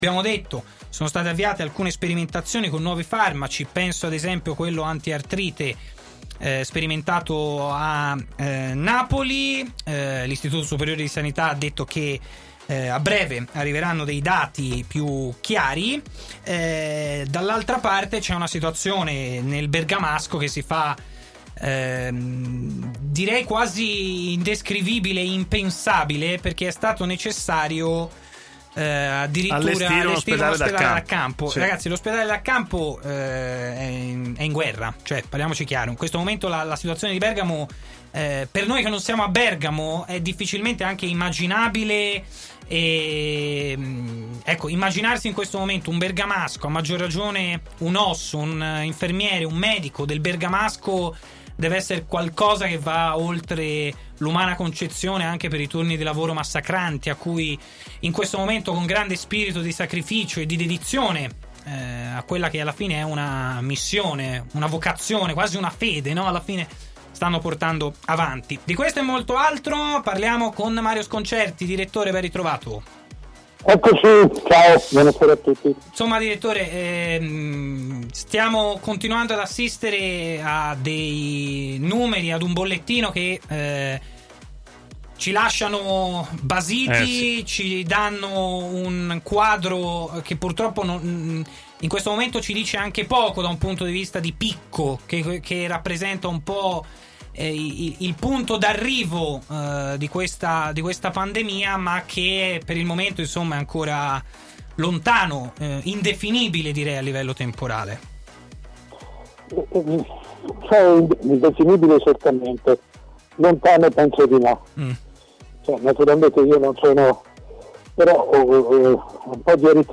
A parlare dell'attuale emergenza sanitaria mondiale è Mario Sconcerti, prima firma del giornalismo sportivo italiano.